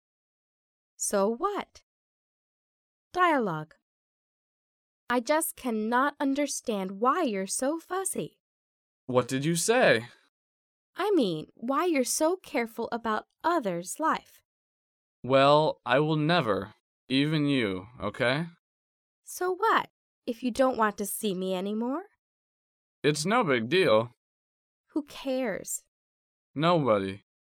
第一，迷你对话